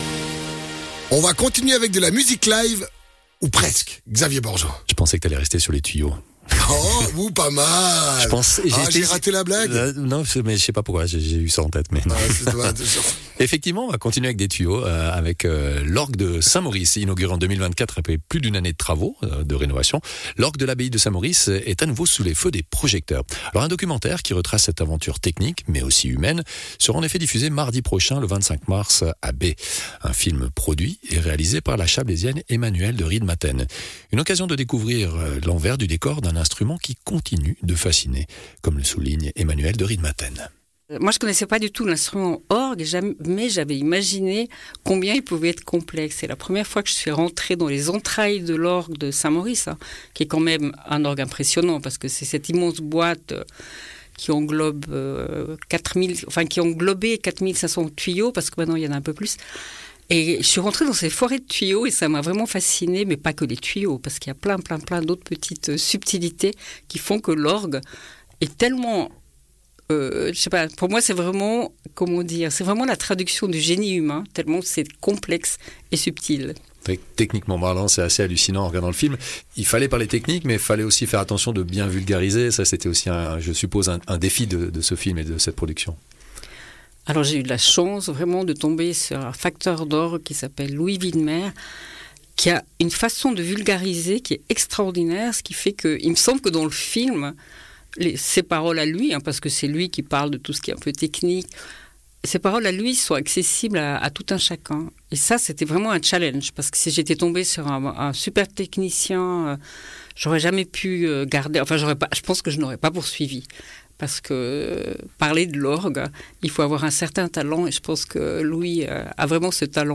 réalisatrice et productrice de films documentaires